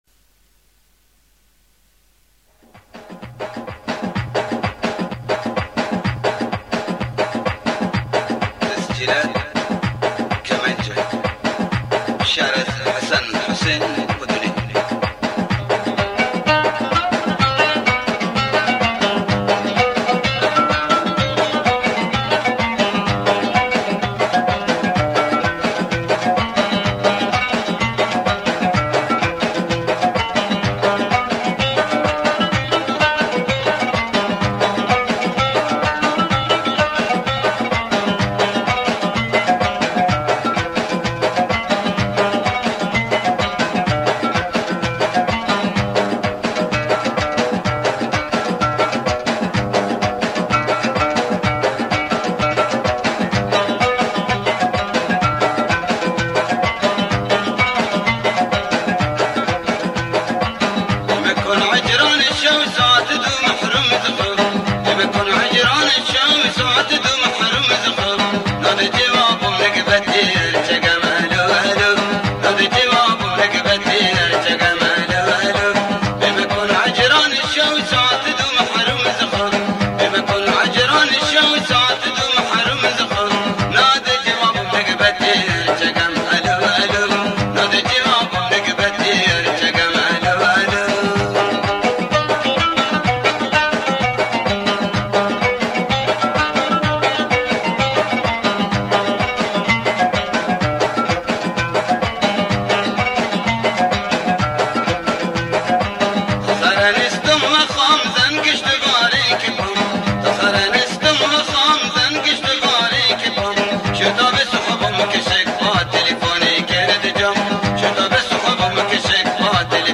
شاد بندری